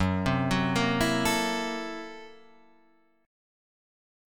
F#7#9b5 chord